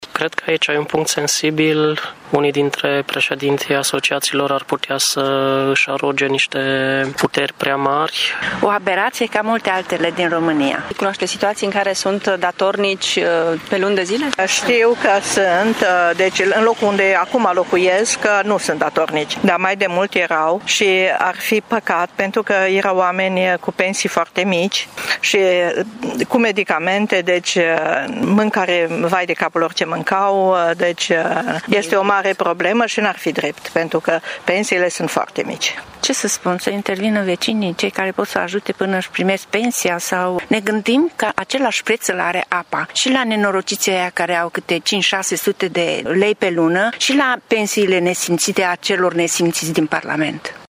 Târgumureșenii sunt revoltați de noua prevedere și spun că ar trebui mai întâi rezolvată cauza pentru care se ajunge la astfel de situații deoarece cei mai mulți dintre cei vizați sunt pensionari cu venituri mici: